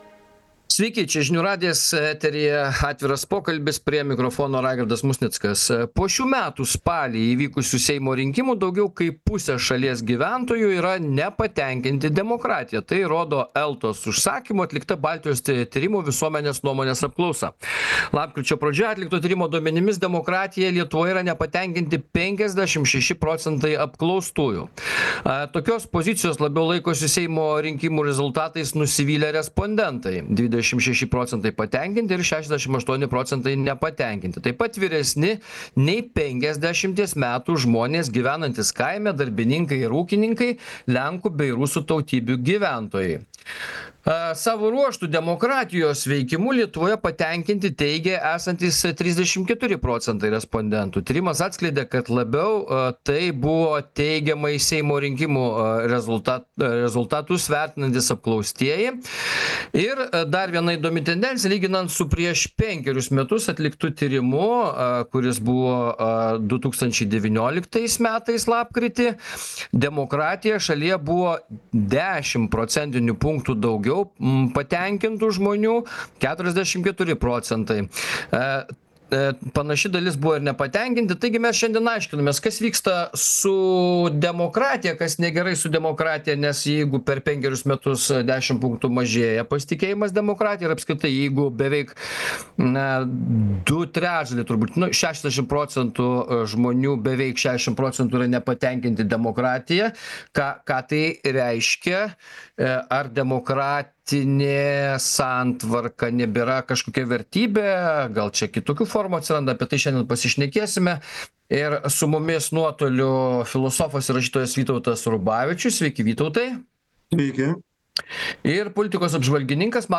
Diskutuoja: filosofas
politikos apžvalgininkas